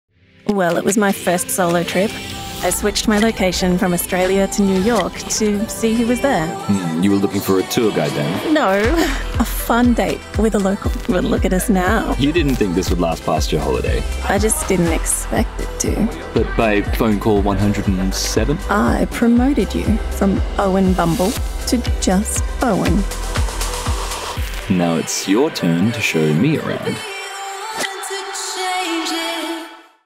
Female
Television Spots
Bumble - Conversational & Sexy
Words that describe my voice are Medical narration expert, Warm and comfortable, Authentic Australian.